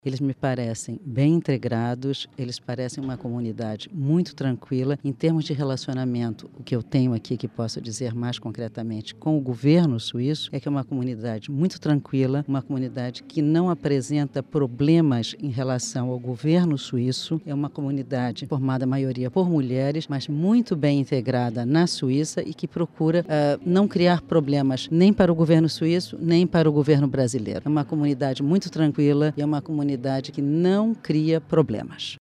A embaixadora do Brasil em Berna, Maria Stela Pompeu Brasil Frota, diz que os brasileiros estão bem integrados na Suíça.